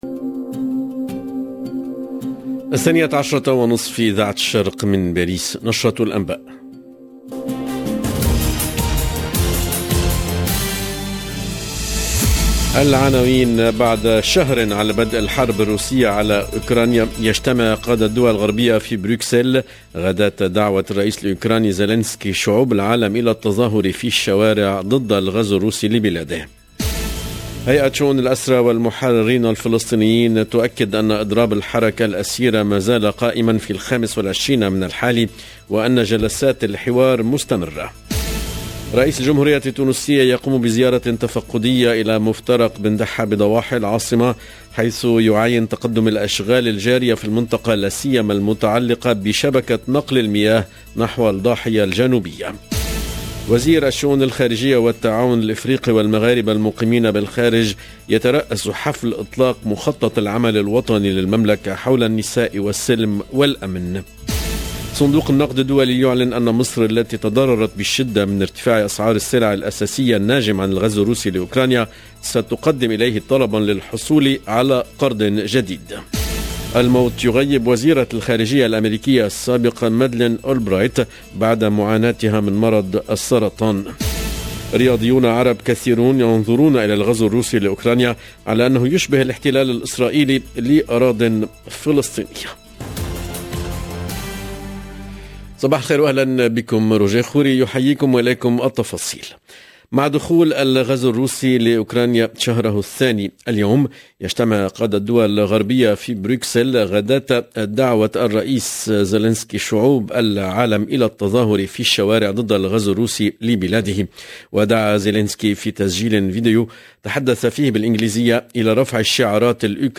EDITION DU JOURNAL EN LANGUE ARABE DU 24/3/2022